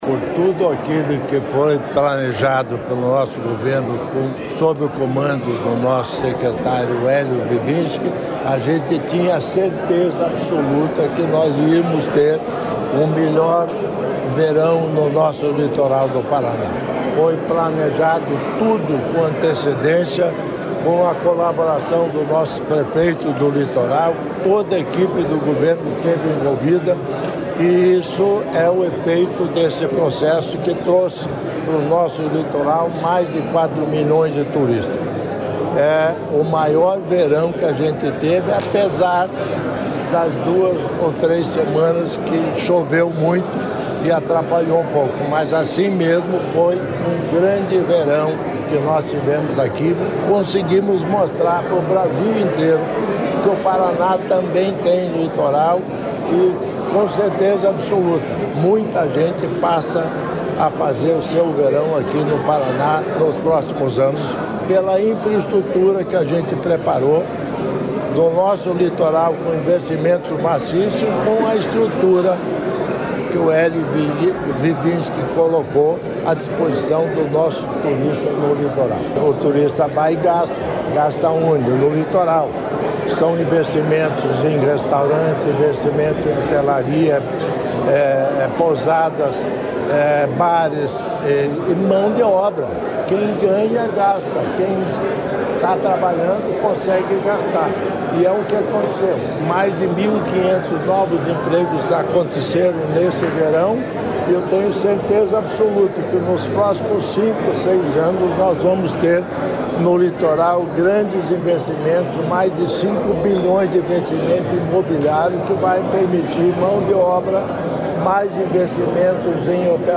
Sonora do vice-governador Darci Piana sobre o balanço do Verão Maior Paraná 2023/2024